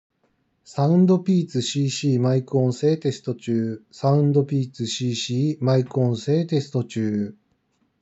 ✅「SOUNDPEATS CC」マイク性能